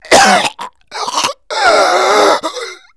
PAINHANG1.WAV